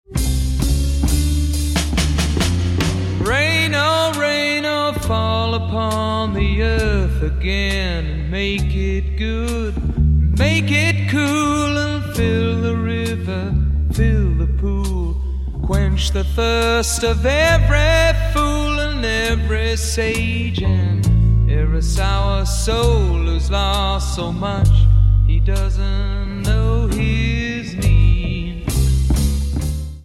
guitar, piano, vocals
flute, piano, organ, saxophone, vocals
drums
bass, guitar